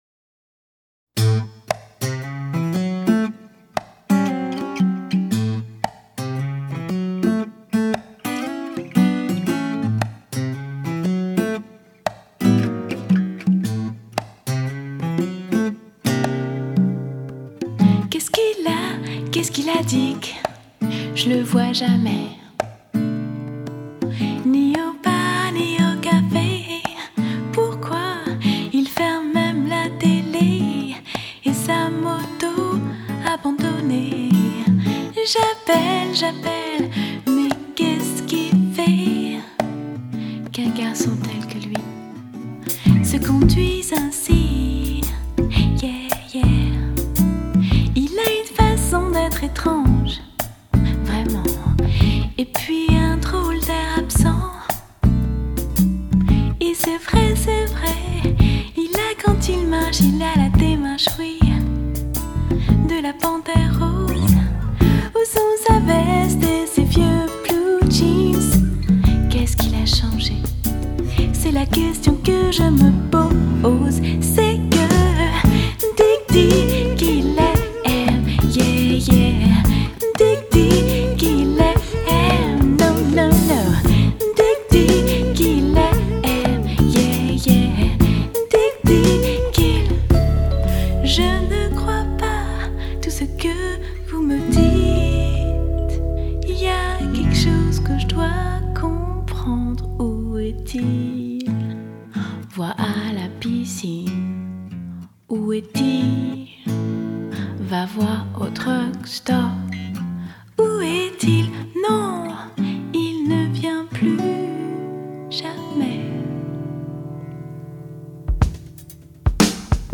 Джаз
Работает в стиле SWING.